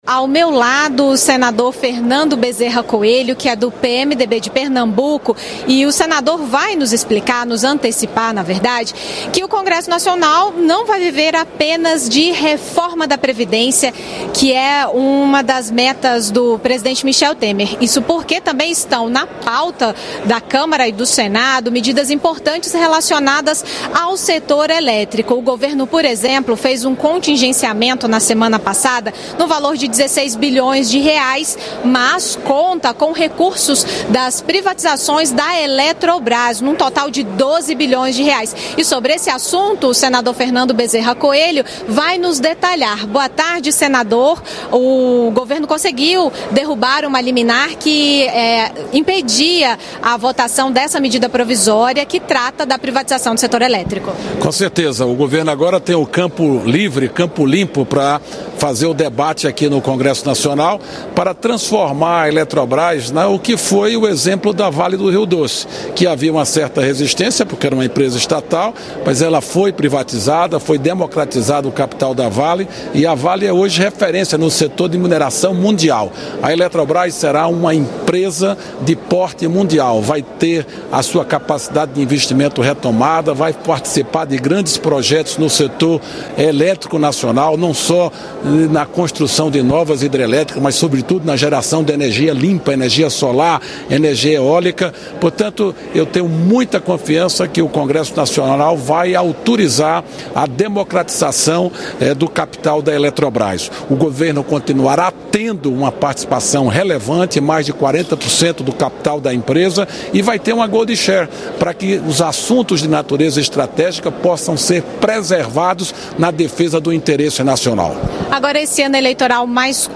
Fernando Bezerra Coelho foi entrevistado
nos minutos que antecedem a sessão solene no Congresso que marca o início do ano legislativo de 2018.